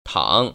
[tăng] 탕  ▶